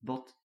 bot SSB pod, ’bot generraly too open